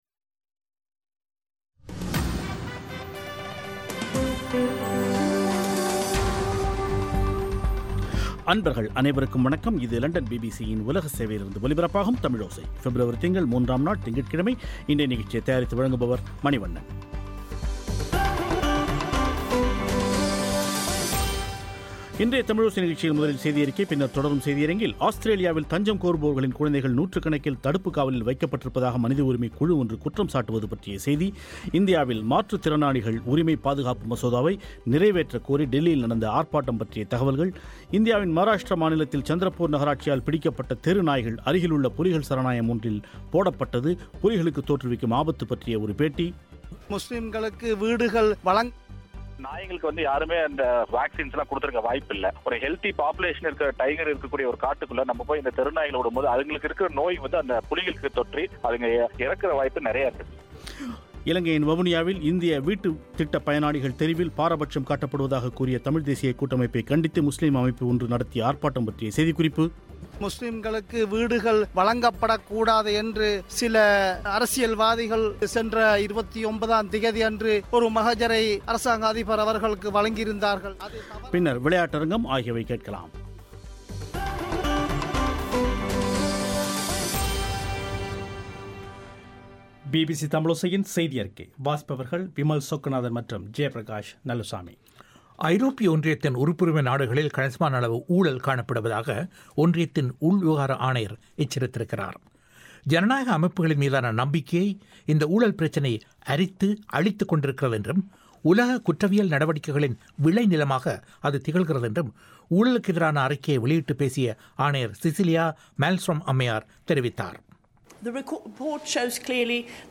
ஒரு பேட்டி